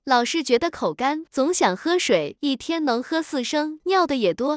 tts_result_5.wav